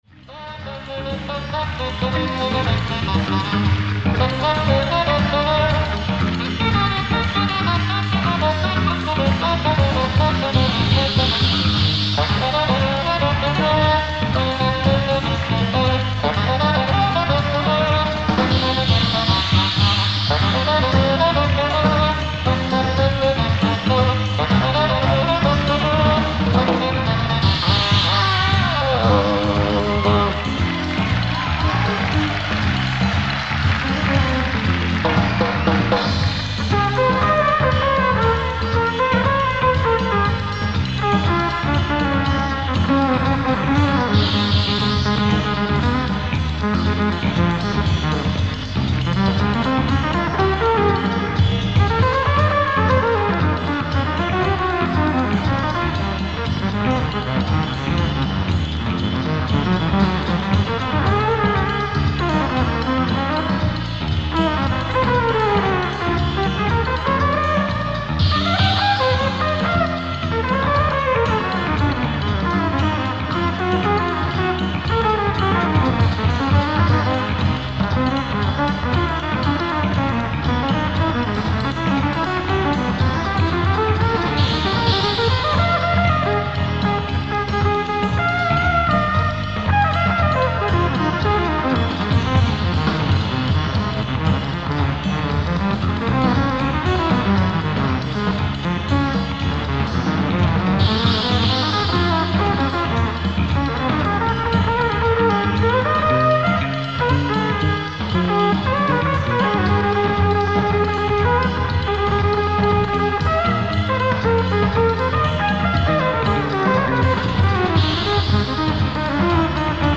ライブ・アット・オープンシアター・イースト、東京
※試聴用に実際より音質を落としています。